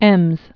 (ĕmz, ĕms)